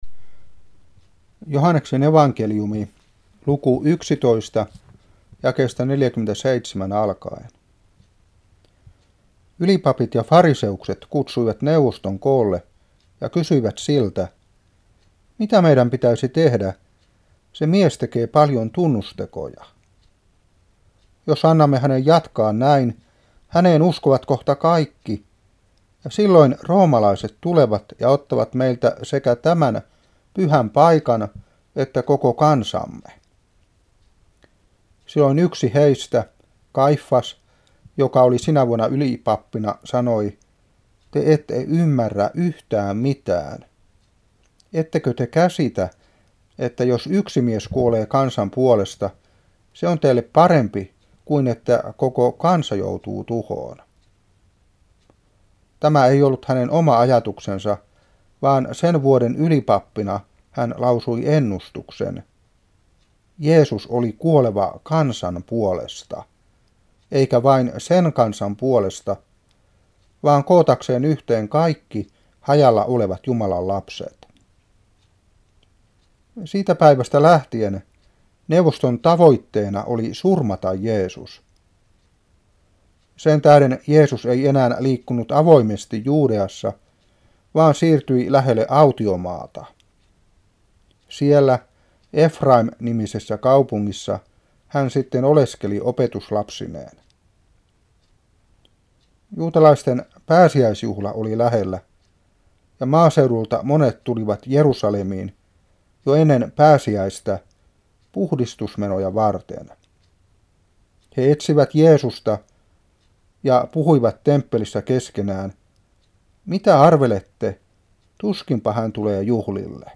Saarna 1992-4.